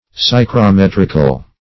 Search Result for " psychrometrical" : The Collaborative International Dictionary of English v.0.48: Psychrometrical \Psy`chro*met"ric*al\, a. Of or pertaining to the psychrometer or psychrometry.